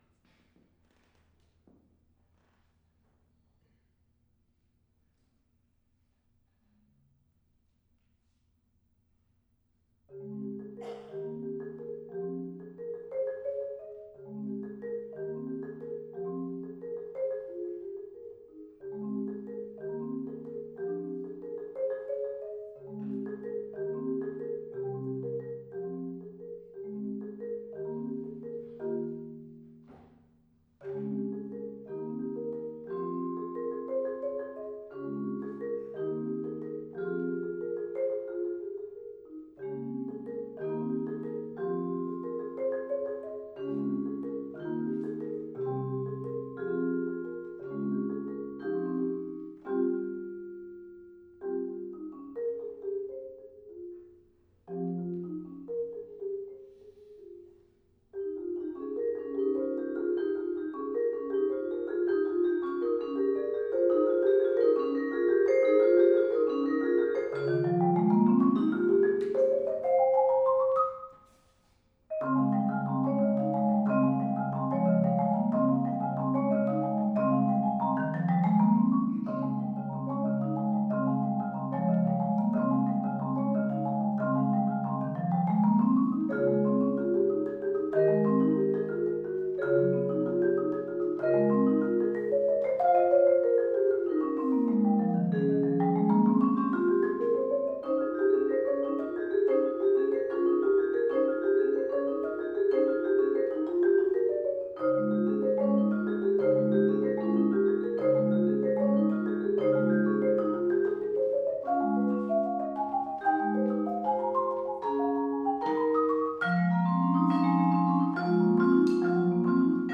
Vertical River (duet for marimba and vibraphone
performed live